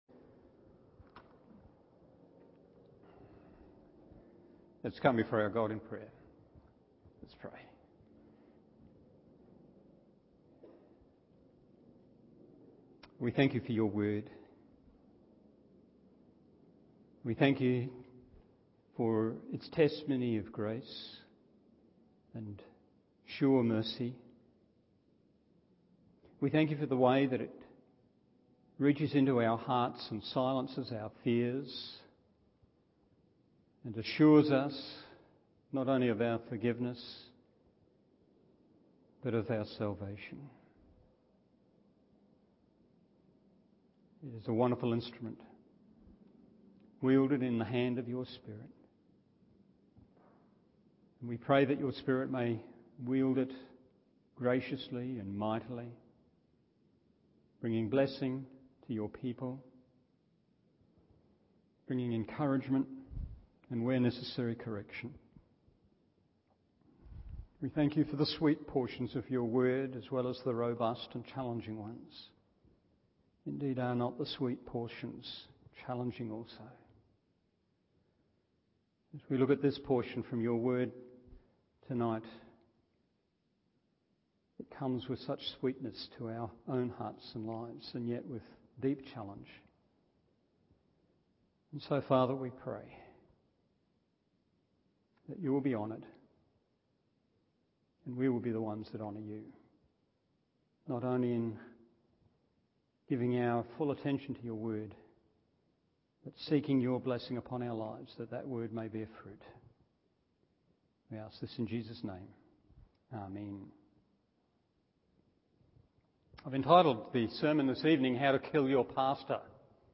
Evening Service 1 Thessalonians 3:6-10 1.